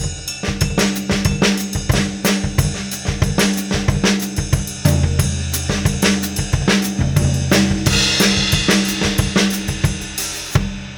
batucada.m4a